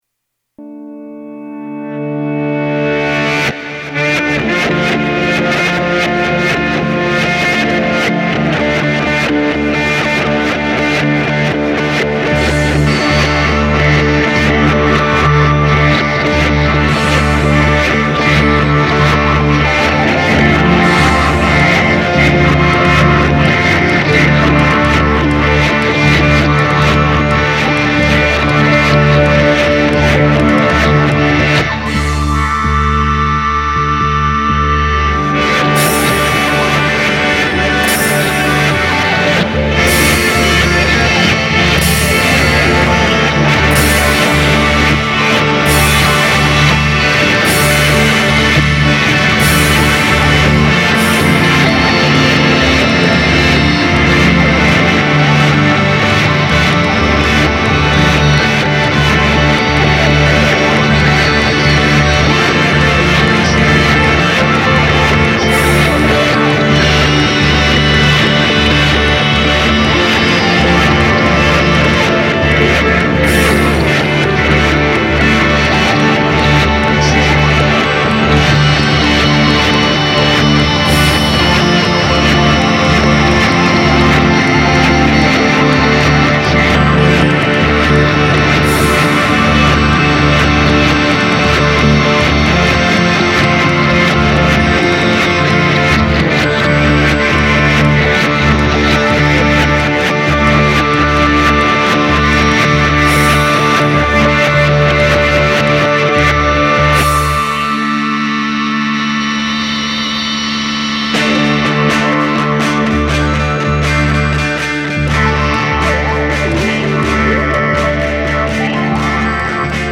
Genre: Noise